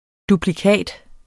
Udtale [ dubliˈkæˀd ]